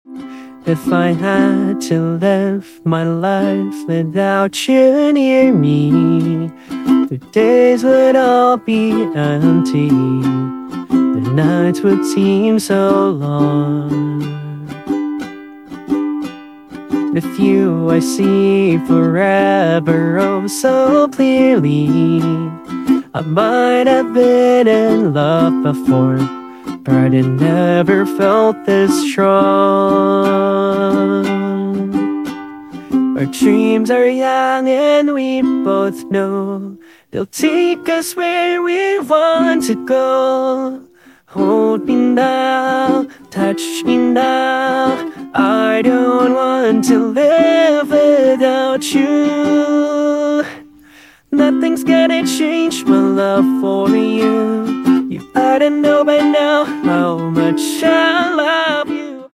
AI Song Cover